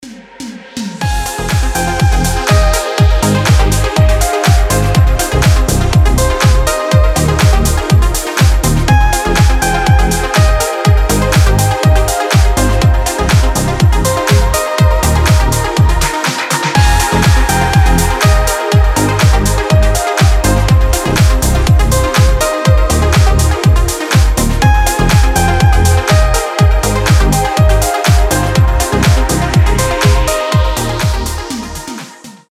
• Качество: 320, Stereo
громкие
deep house
без слов
красивая мелодия
nu disco
Мелодичный звонок